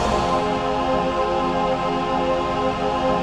VOICEPAD08-LR.wav